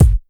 Kick_98.wav